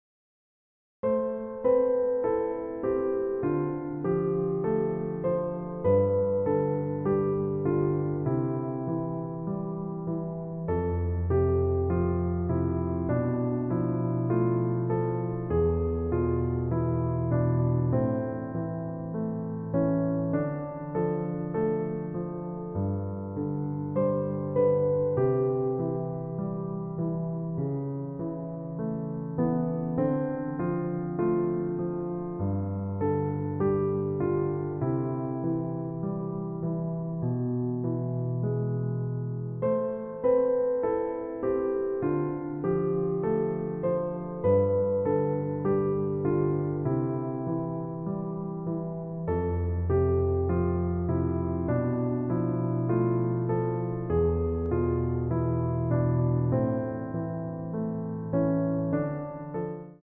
EASY MEDIUM Piano Tutorial